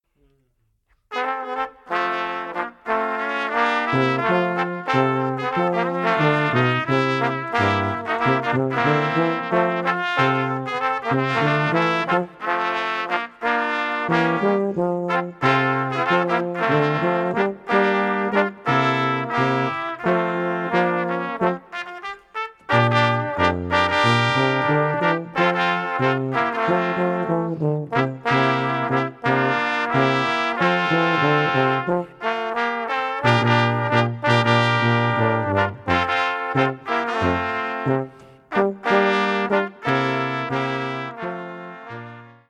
für Blechbläserquartett.
Paritur in C und Stimmen für 2 Trompeten und 2 Posaunen.